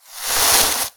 fireball_conjure_02.wav